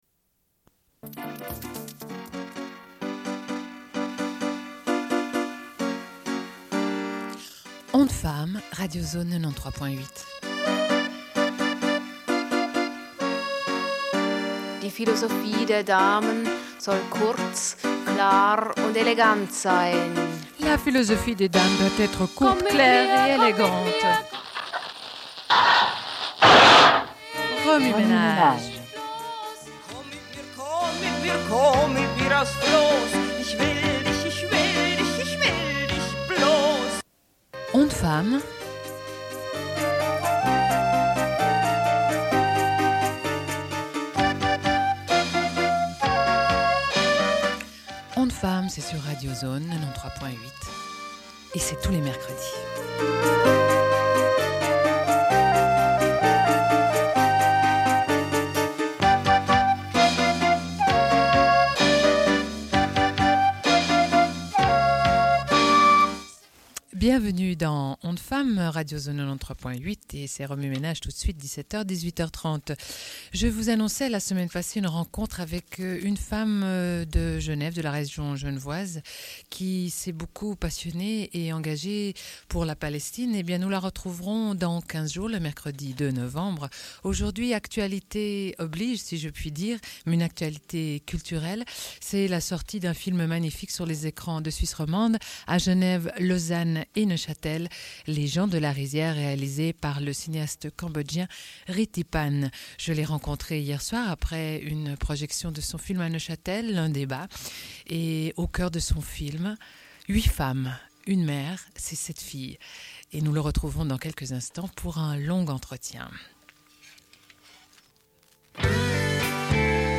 Une cassette audio, face A31:21
Sommaire de l'émission : diffusion d'un entretien avec Rithy Panh, cinéaste cambodgien, au sujet de son film Les gens de la rizière.